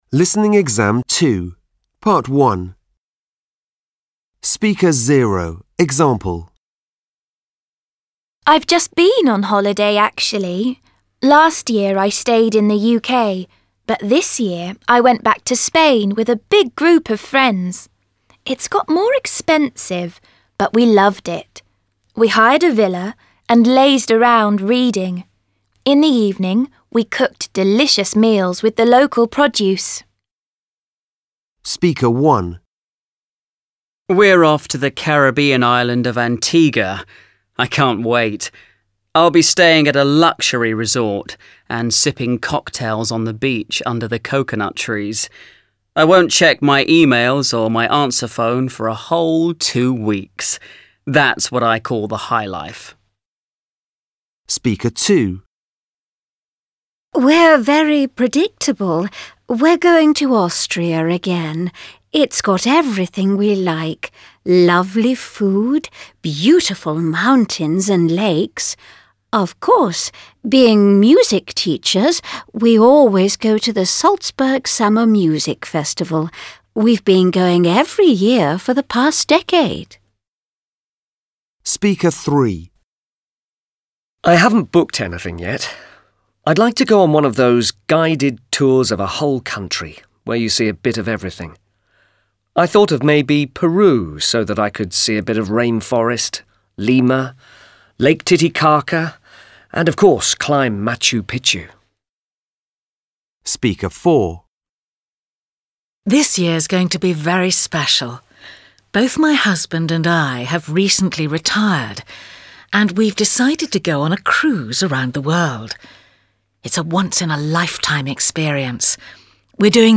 You are going to listen to some people talking about their